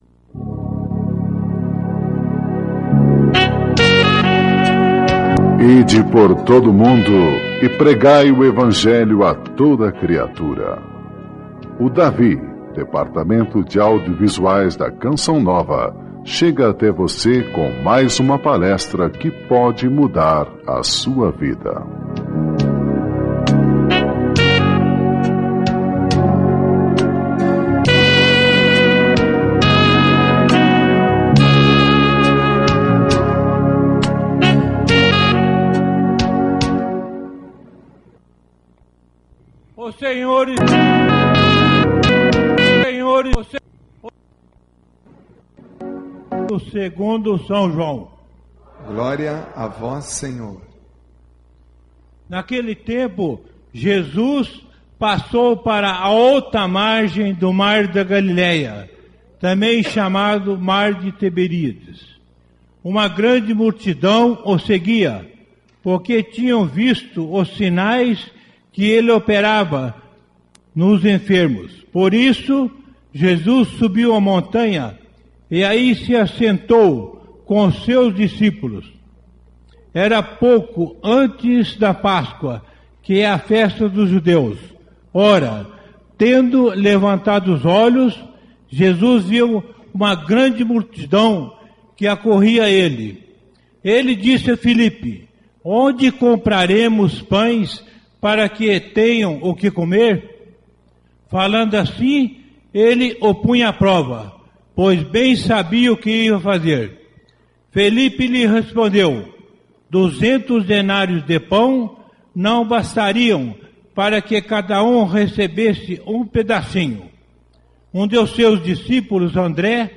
Ouça a palestra com o monsenhor Jonas Abib da Canção Nova